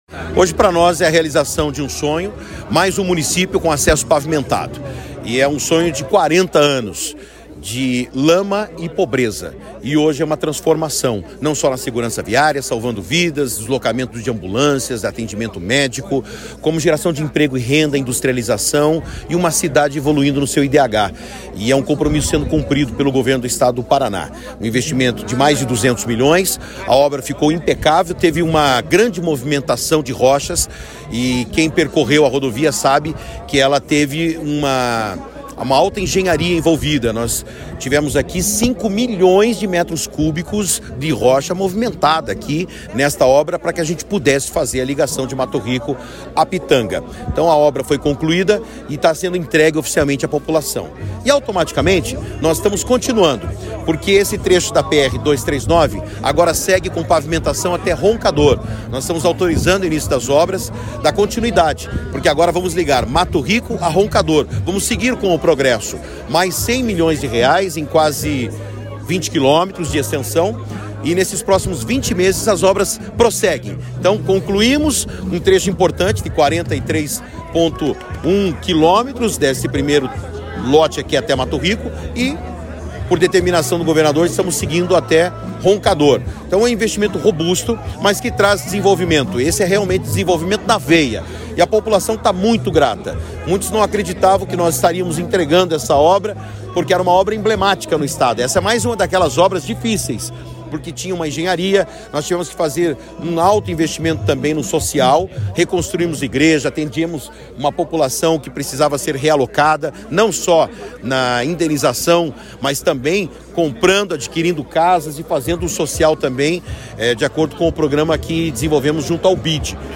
Sonora do secretário de Infraestrutura e Logística, Sandro Alex, sobre as obras na PR-239